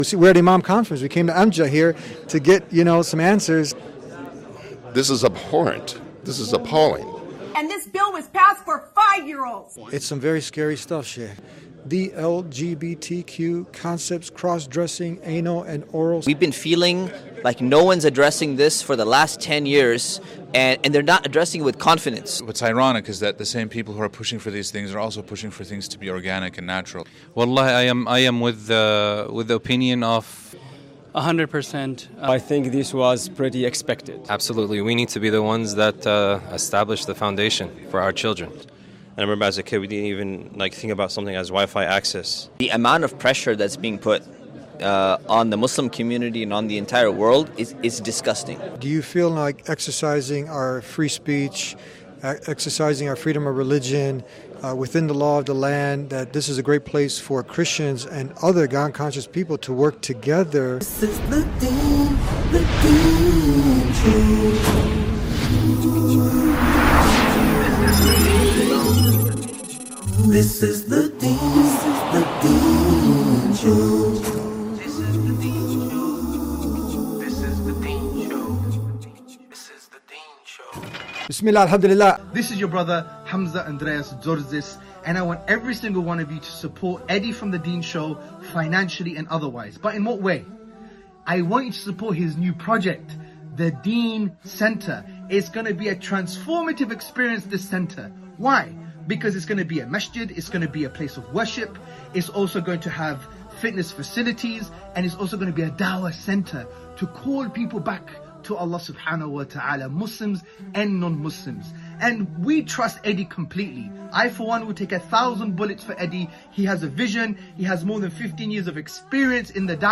At the AMJA (Assembly of Muslim Jurists of America) convention, Muslim imams and scholars share their reactions to the shocking news that Chicago public schools will begin teaching kindergartners about gender identity, third graders about hormone blockers, and sixth graders about anal and oral sex under Governor Pritzker’s new curriculum. Their responses range from alarm to practical solutions grounded in Islamic guidance.